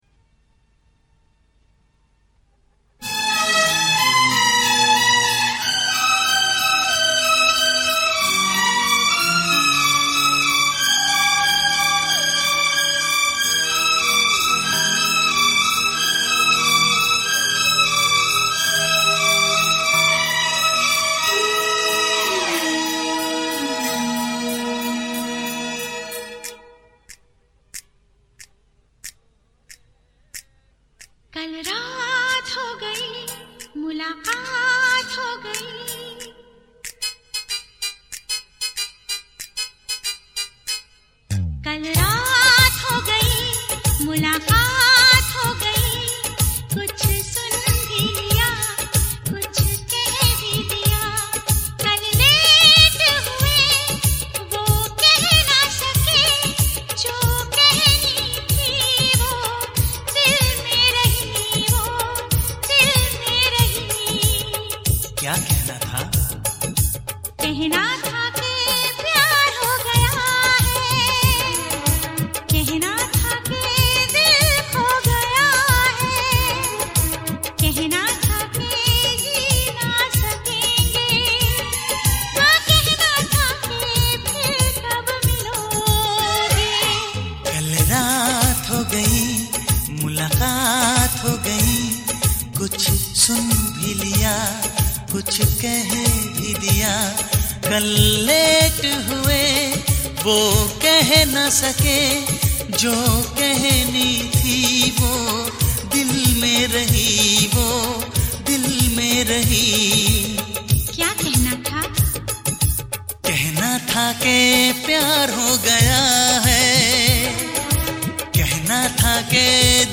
Soft romantic love song